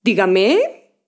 Mujer hablando por teléfono
mujer
Sonidos: Voz humana
Elementos de telefonía